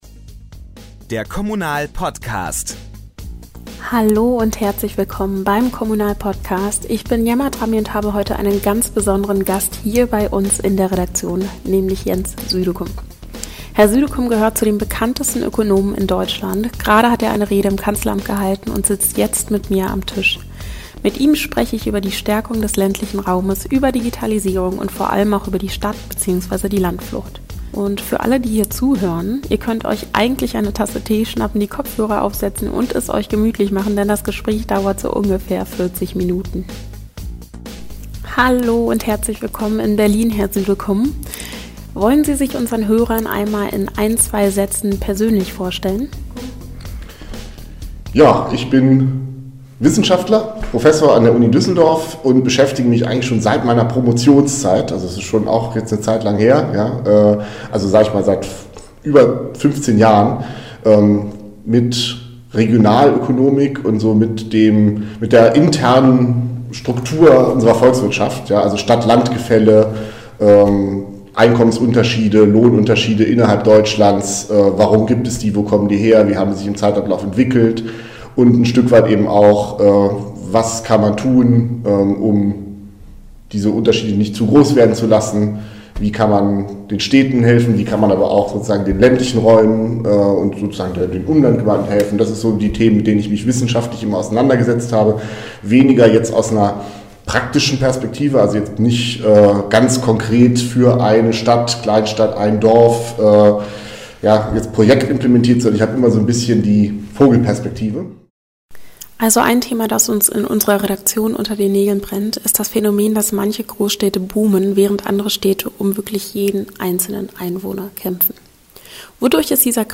Jens Südeküm im KOMMUNAL-Interview über Stadt und Dorf, Digitalisierung und die Frage, warum die Zukunft des Landlebens mit den richtigen Weichenstellungen gerade erst begonnen hat!